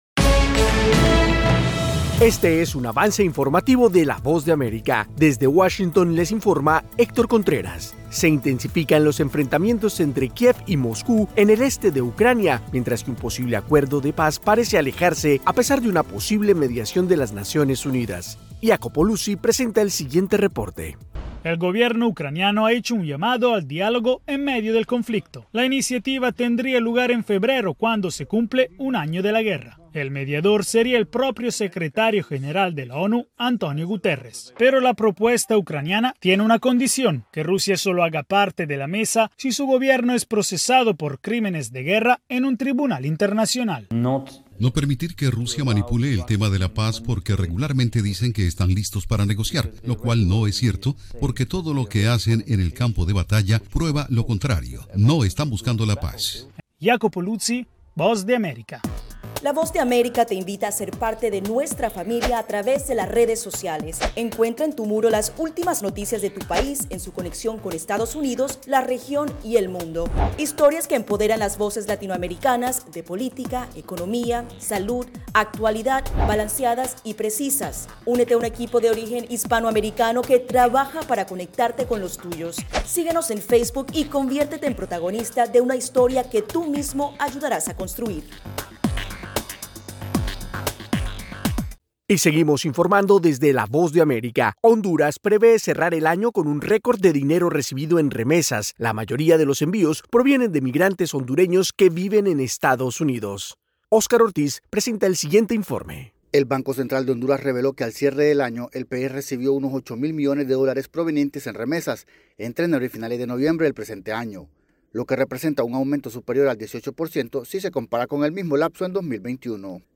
Avance informativo 6:00 AM
Este es un avance informativo presentado por la Voz de América en Washington.